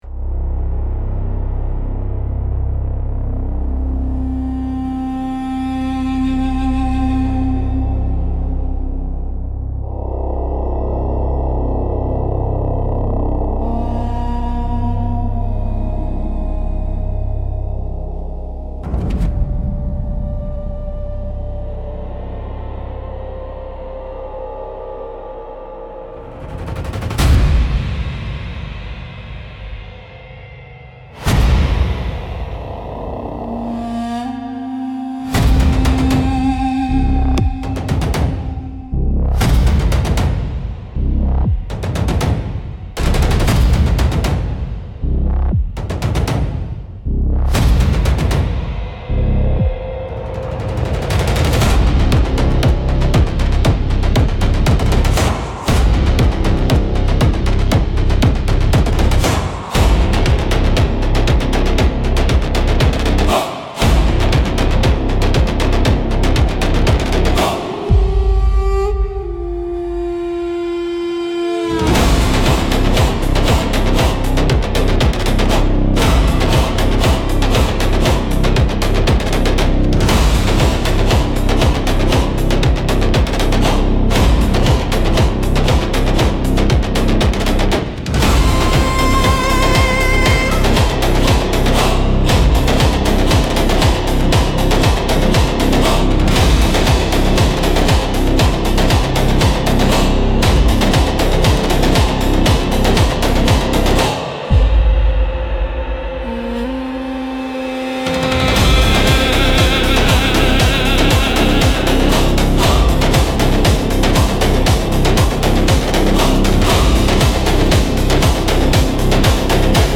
ritual